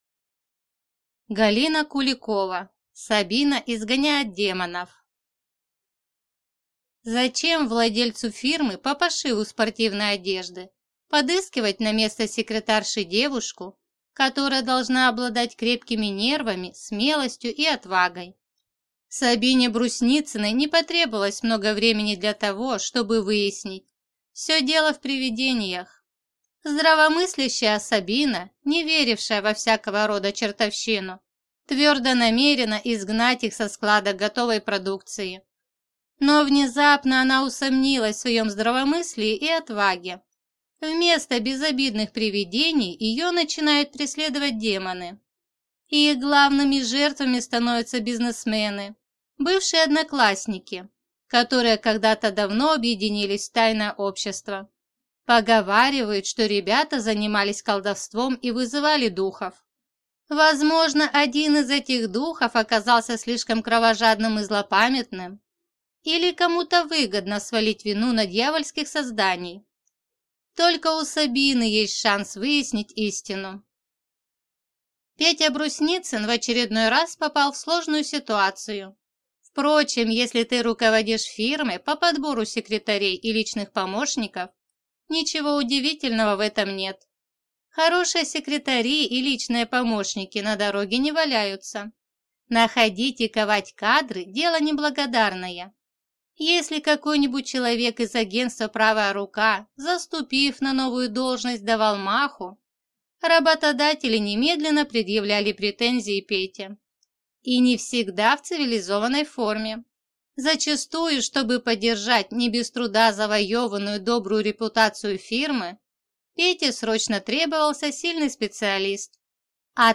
Аудиокнига Сабина изгоняет демонов | Библиотека аудиокниг